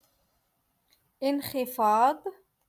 Moroccan Dialect - Rotation Two- Lesson Fifty One